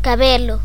pronunciación (axuda · info)) ou pelo[2] é a continuación da pel cornificada, e contén unha fibra de queratina, constituída por unha raíz e unha base, que se forma nun folículo da derme, e constitúe o trazo característico da pel.
Gl-cabelo.ogg